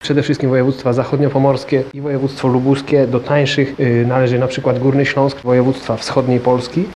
– przyznaje jeden z ukaranych. W tym przypadku kierowca dostał 6 punktów karnych i mandat w wysokości 300 złotych.